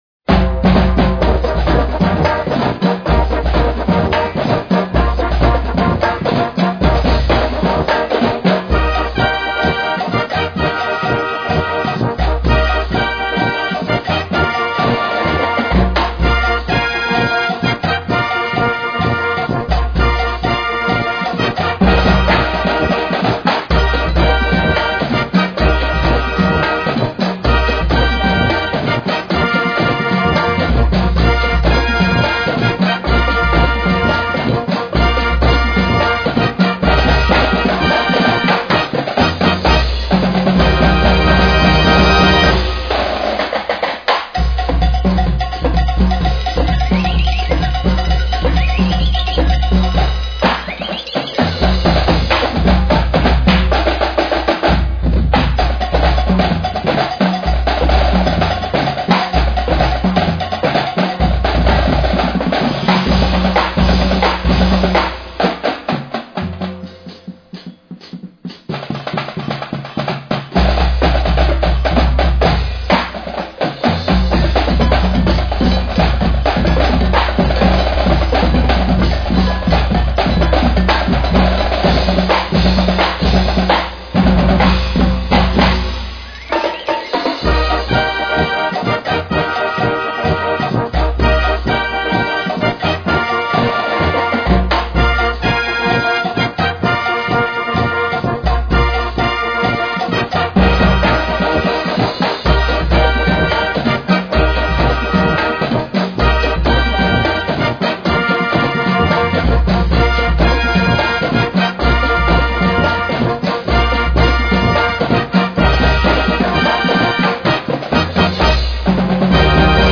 Besetzung: Naturtonfarenzug.
Musik für Naturton-Fanfarenzüge